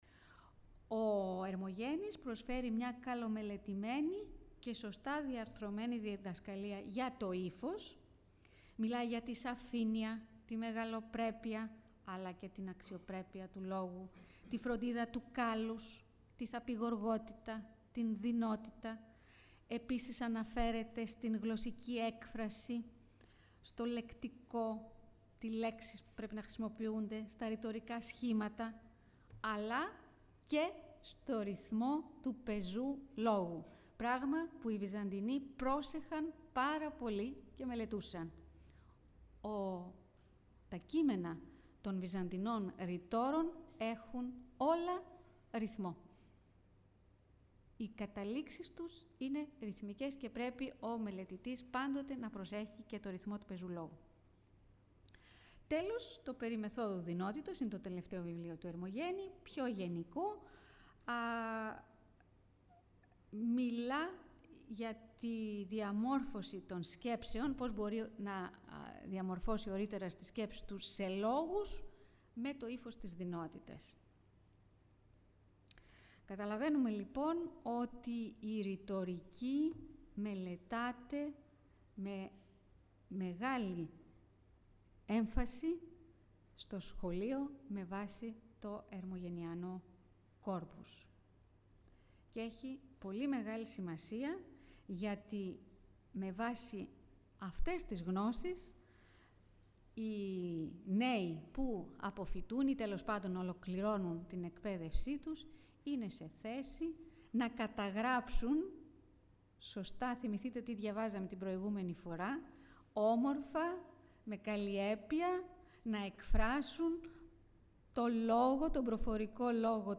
9 June 2004 at the chamber Theotokopoulos two lectures with the subject: «Schools and education in Constantinople during the Komninoi period»